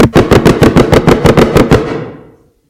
描述：Laser Automatic Fast
标签： Automatic small Rifle Machine Fast Gun Weapon Auto SciFi Laser
声道立体声